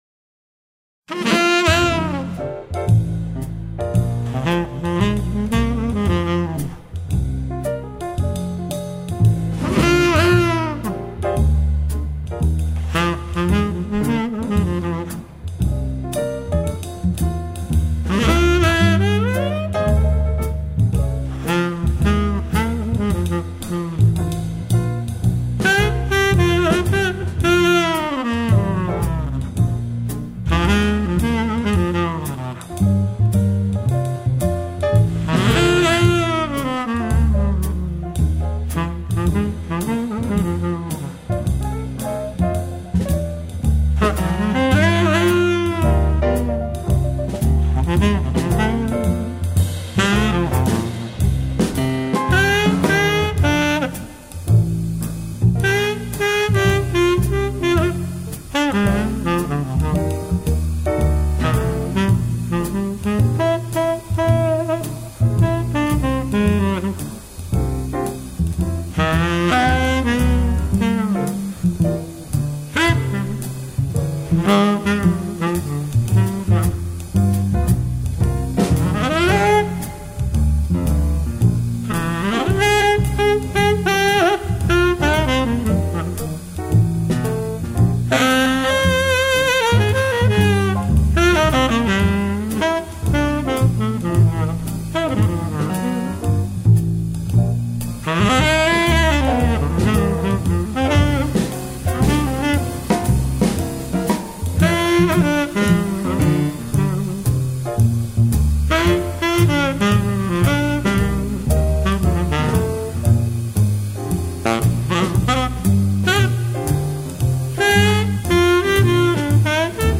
saxophone ténor
trompette
piano
contrebasse
batterie